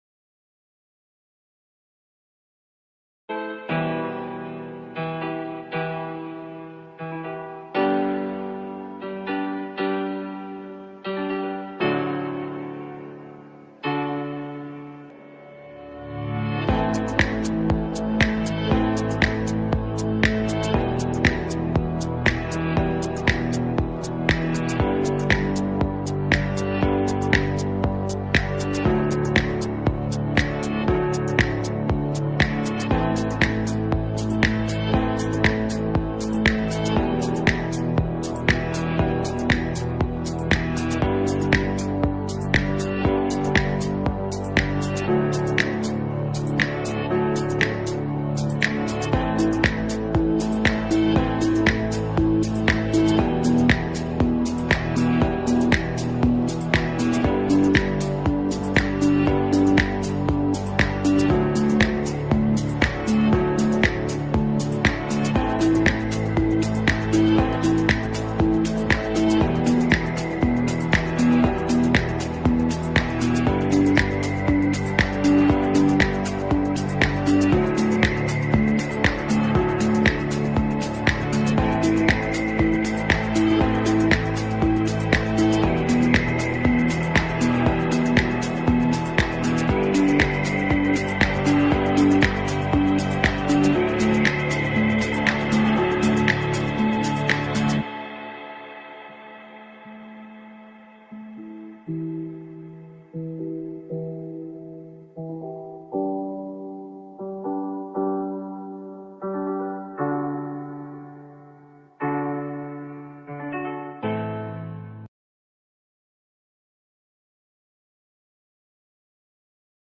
1 Bullish but Limited Volume as Markets Await NFP Numbers | Pre-Market Trade Plan Live | 3rd July 2025 28:14 Play Pause 7h ago 28:14 Play Pause Play later Play later Lists Like Liked 28:14 This daily podcast is broadcast live to our traders at 7:30 a.m. (UK Time) every morning.